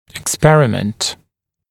[ɪk’sperɪmənt] [ek-][ик’спэримэнт] [эк-]эксперимент, опыт; экспериментировать, производить опыт